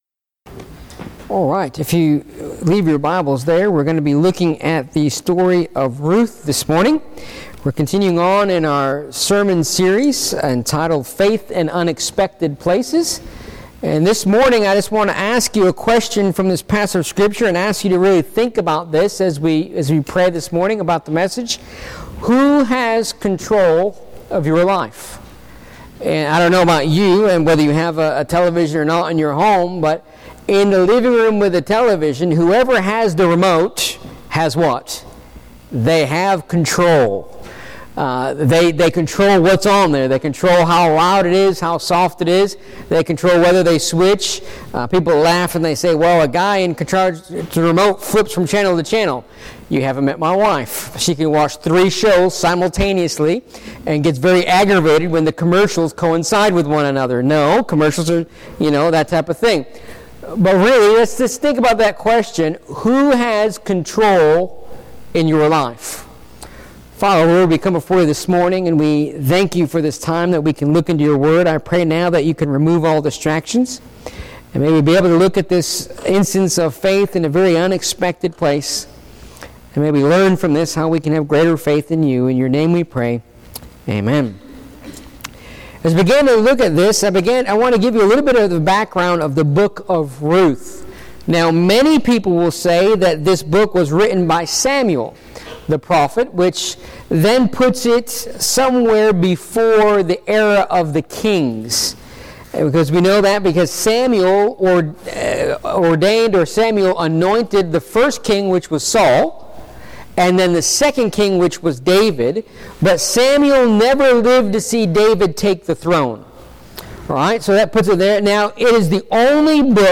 Please note that due to technical difficulties, this sermon may have disruptive static throughout.